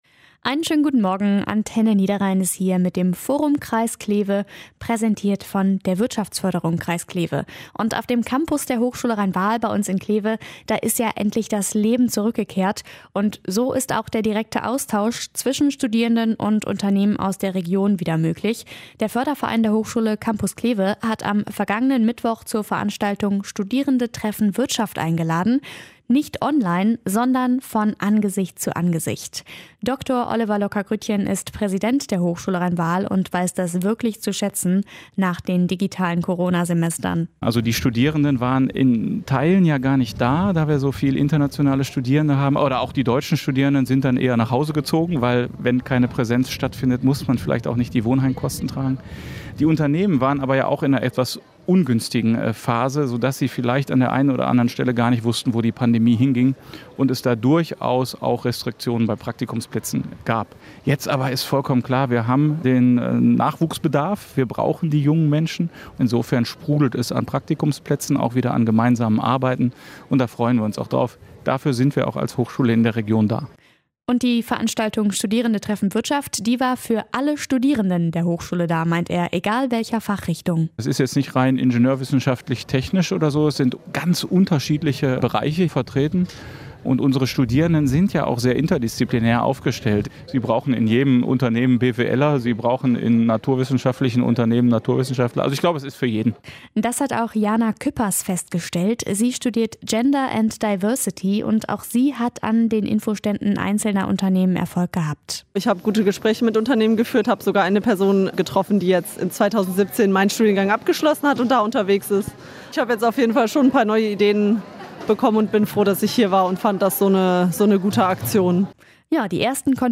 Forum Interview 3